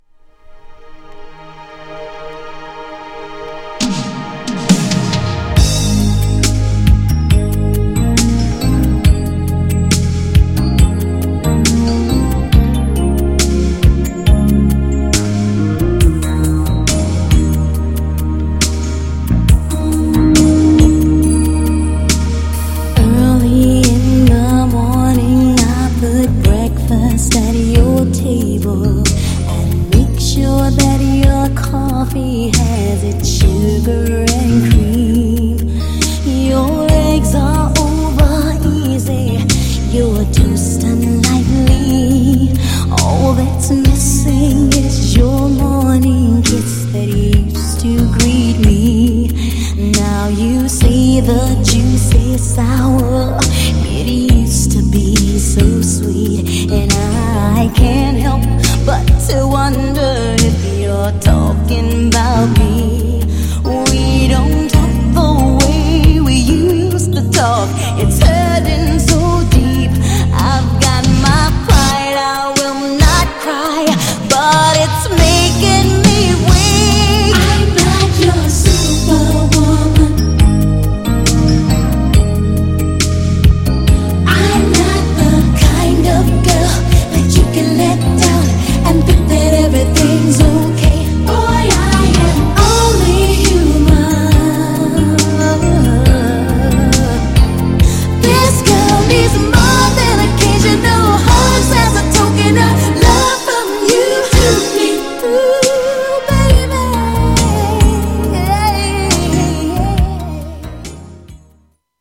GENRE R&B
BPM 86〜90BPM
バラード
女性VOCAL_R&B